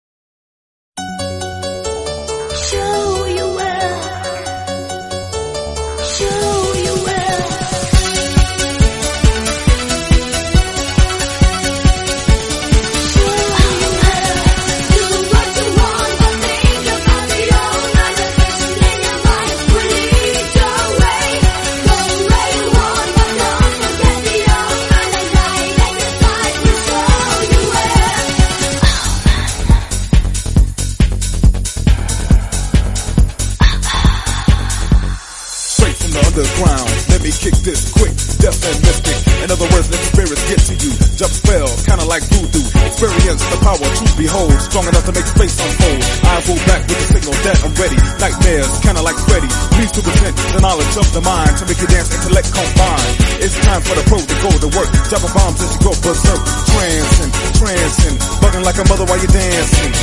A powerful mix of rap + haunting vocals